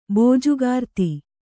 ♪ bōjugārti